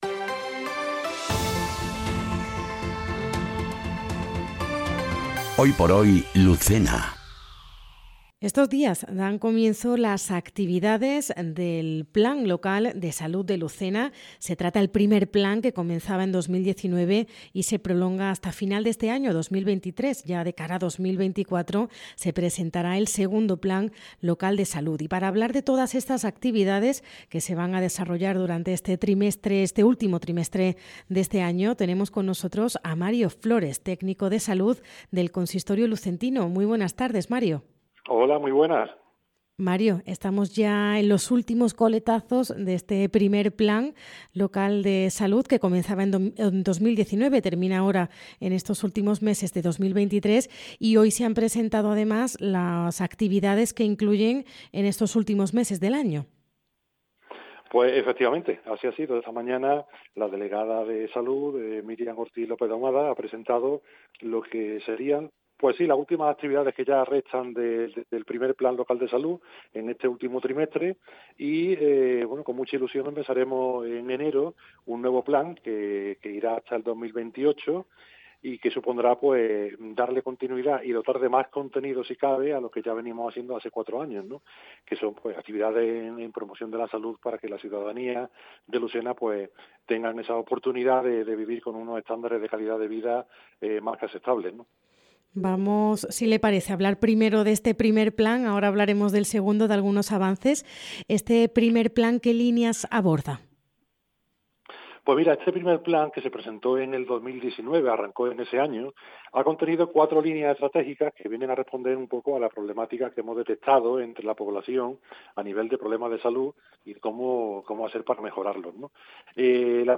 ENTREVISTA | Actividades Plan Local de Salud 2023